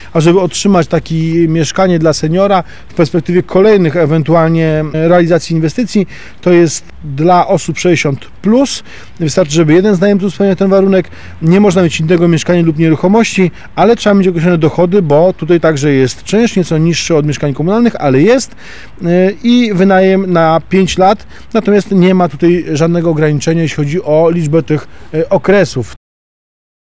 O tym, co muszą zrobić seniorzy, aby otrzymać mieszkanie w przypadku kolejnych realizacji, mówi prezydent Andrzej Nowakowski.